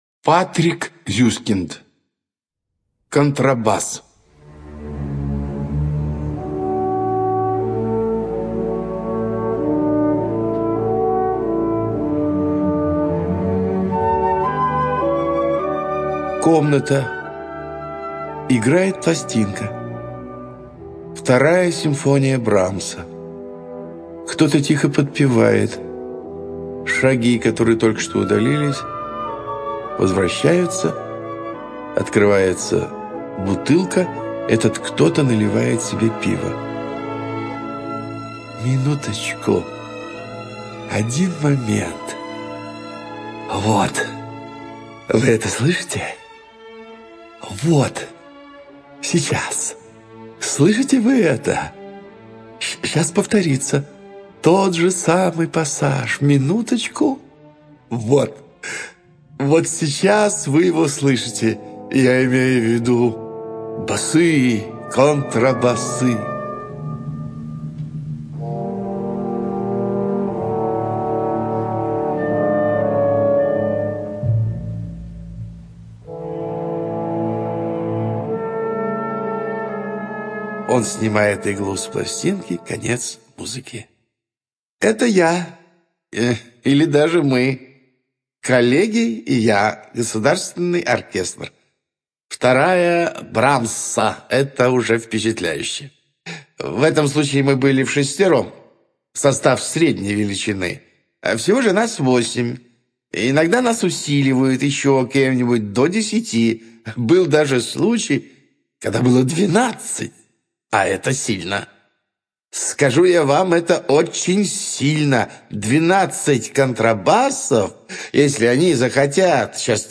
ЧитаетРавикович А.